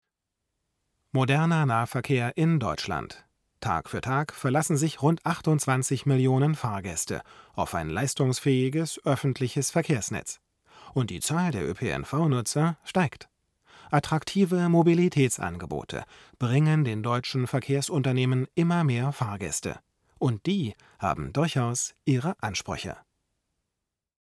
Im aktuellen Beispiel brichst du die Satzbögen ab.
Hab dir das als Beispiel wie ich es meine mal kurz eingesabbelt.